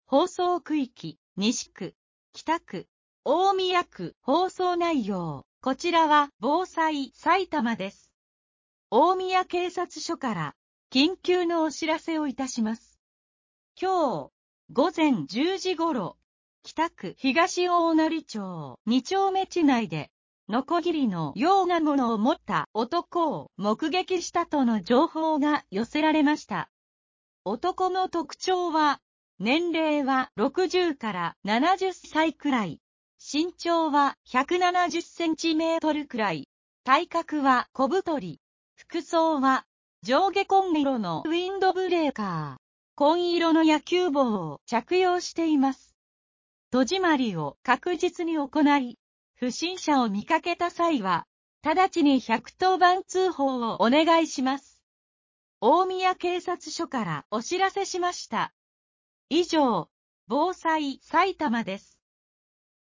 警察からの緊急放送
放送区域：西区、北区、大宮区